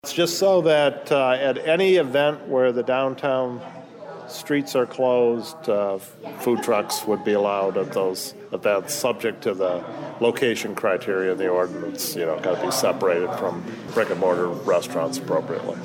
Administrator Greg Elliott talked about those alterations with WLEN News…